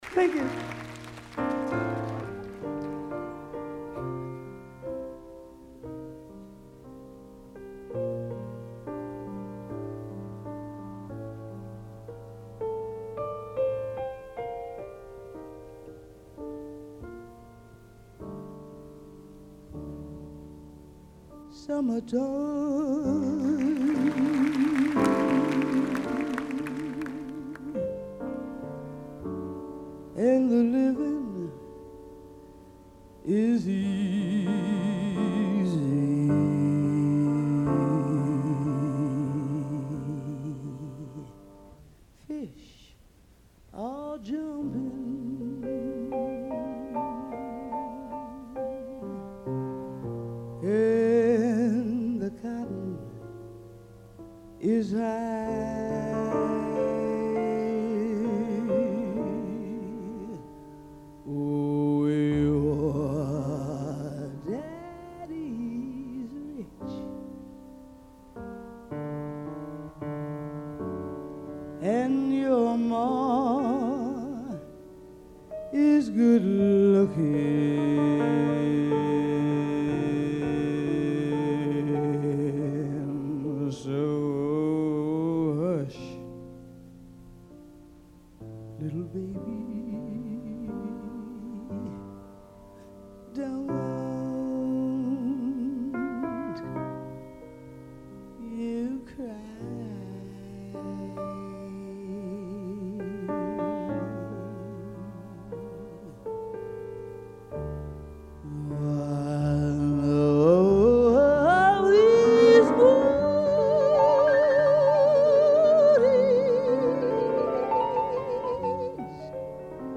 no piano
no contrabaixo
na bateria